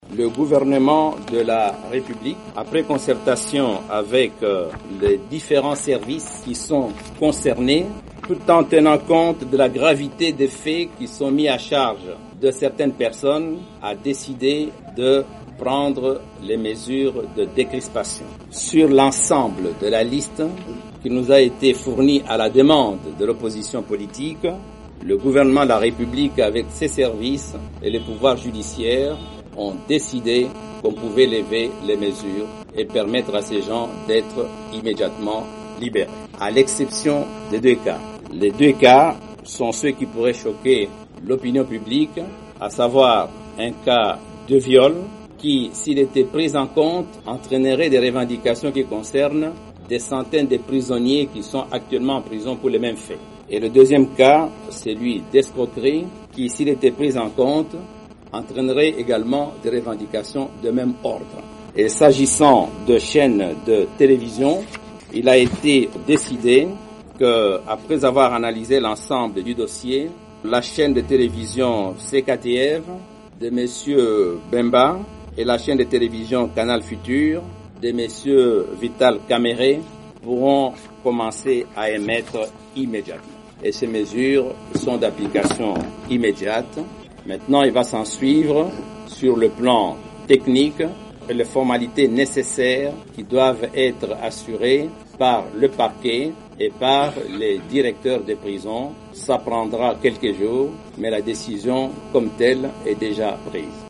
Déclaration de la libération des prisonniers politiques par le ministre congolais de la Justice Alexis Thambwe Mwamba